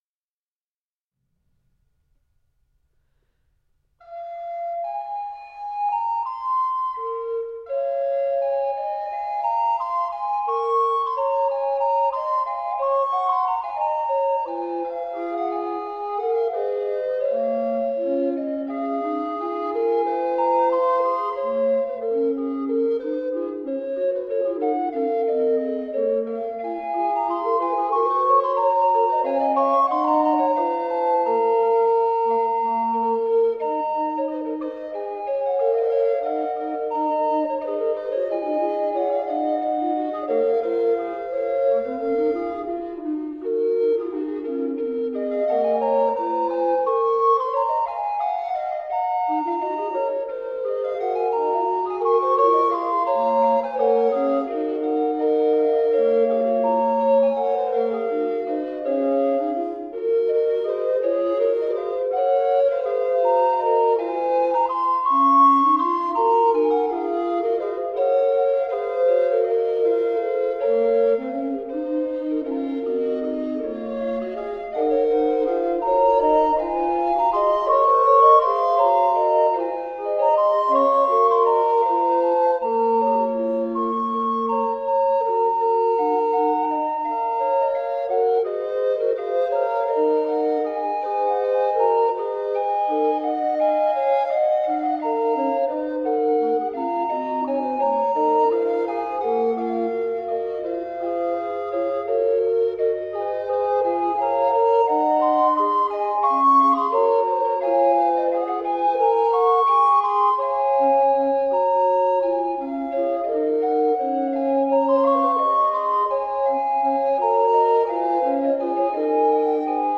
Renaissance recorder ensemble.
Classical, Renaissance, Instrumental, Recorder